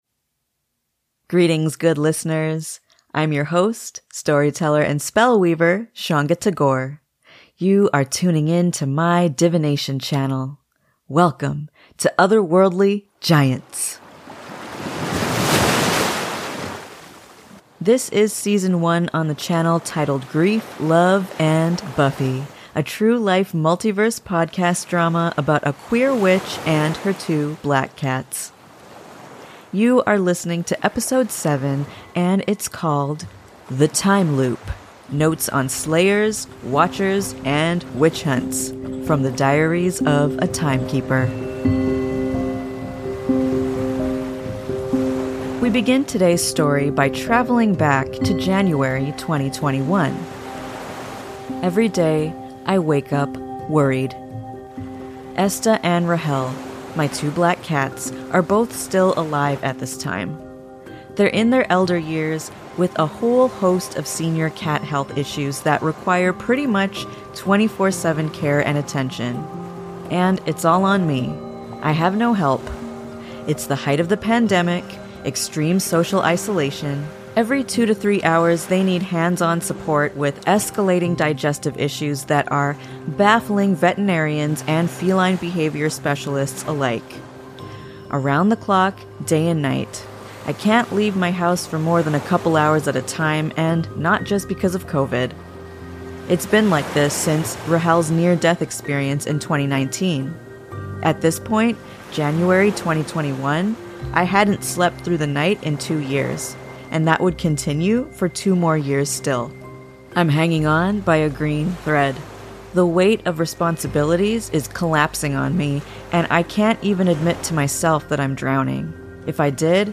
This is your True-Life Multiverse podcast drama about a queer witch and her two black cats <3 Here we blur the lines between fantasy and reality.